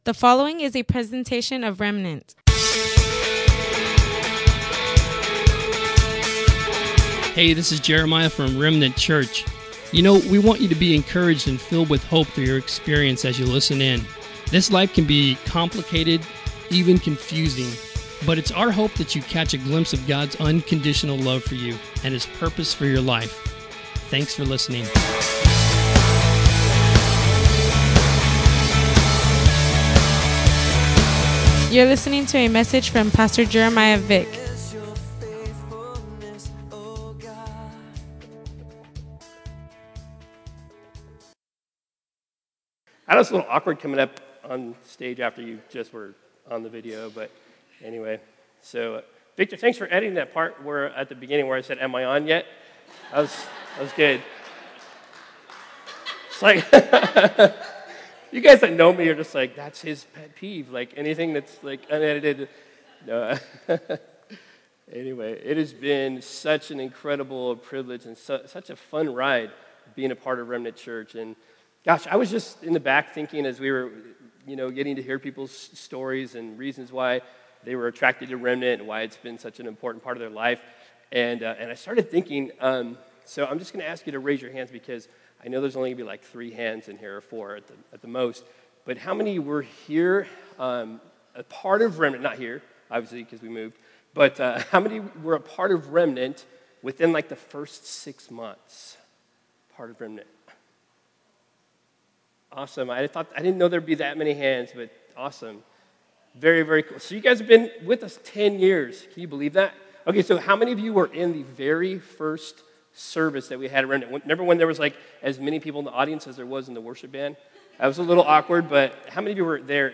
Join us Sunday, April 2nd, as we celebrate 10 years of blessings, challenges and great things God has done in and through Remnant. Plus, we’ll be challenged to aim high as we look to what’s ahead in the future!